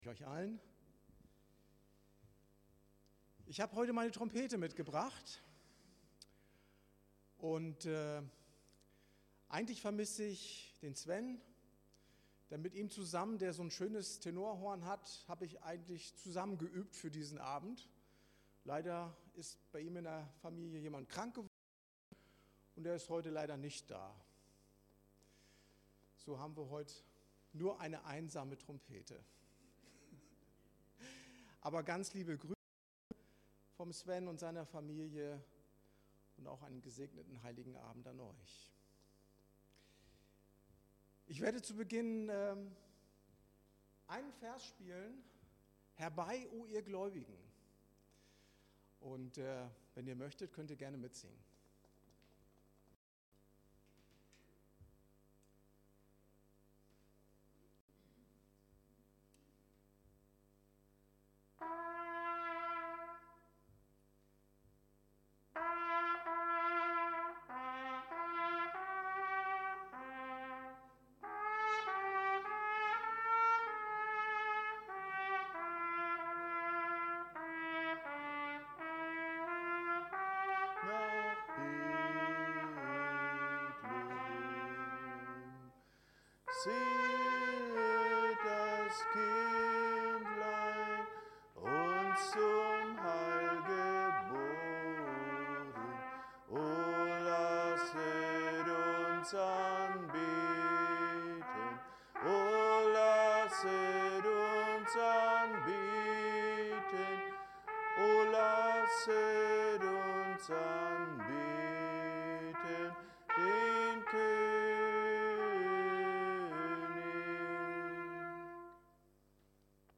Heilig Abend Gottesdienst
Dienstart: Predigt